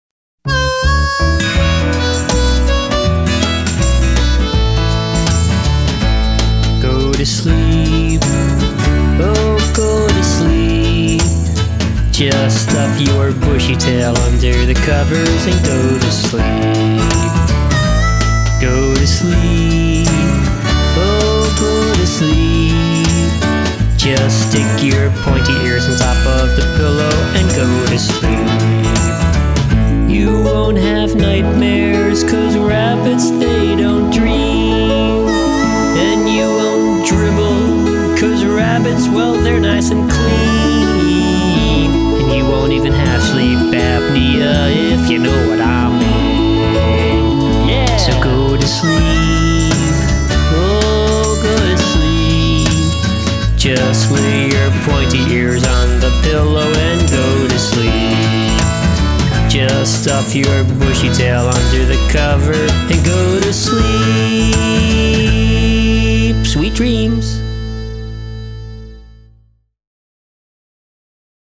I spontaneously sang this song.